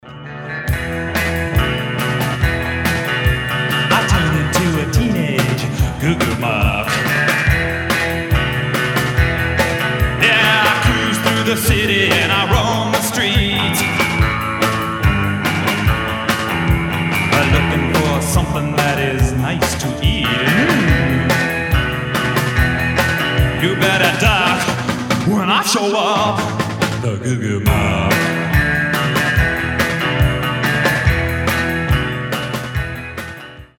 • Качество: 320, Stereo
rockabilly